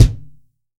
TUBEKICKT5.wav